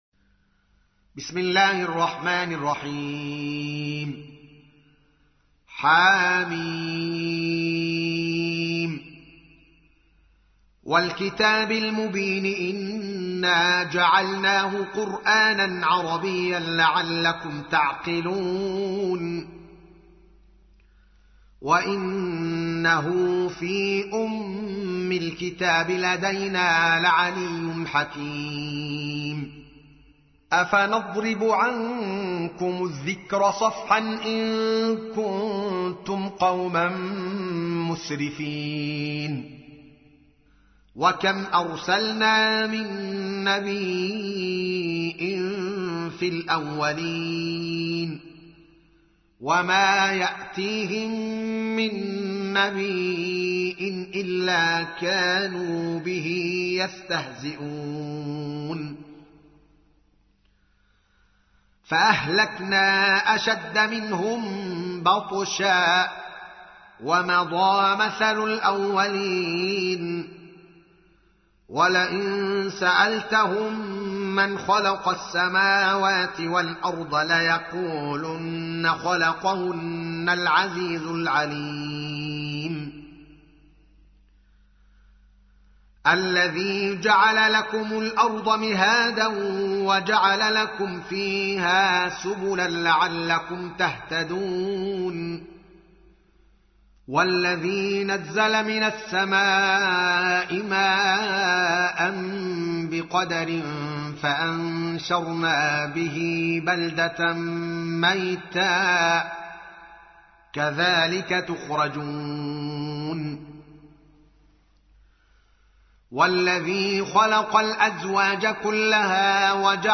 تحميل : 43. سورة الزخرف / القارئ الدوكالي محمد العالم / القرآن الكريم / موقع يا حسين